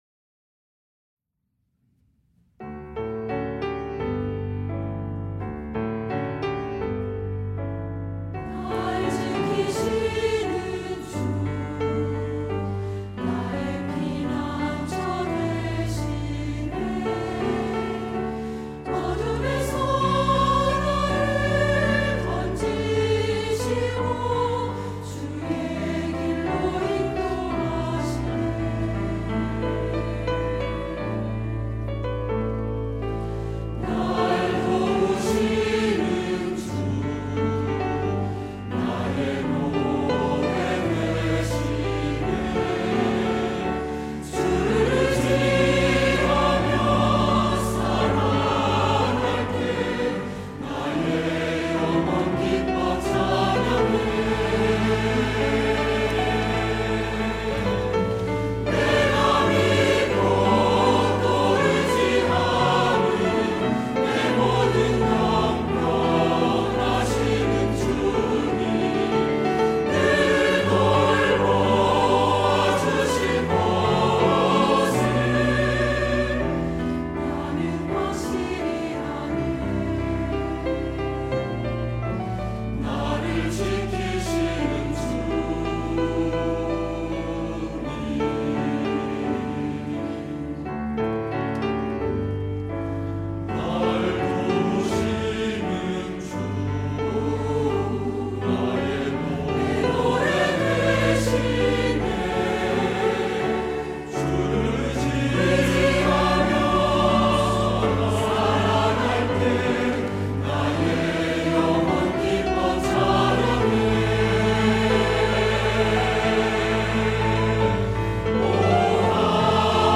할렐루야(주일2부) - 날 지키시는 주
찬양대